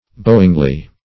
bowingly - definition of bowingly - synonyms, pronunciation, spelling from Free Dictionary
bowingly - definition of bowingly - synonyms, pronunciation, spelling from Free Dictionary Search Result for " bowingly" : The Collaborative International Dictionary of English v.0.48: Bowingly \Bow"ing*ly\, adv. In a bending manner.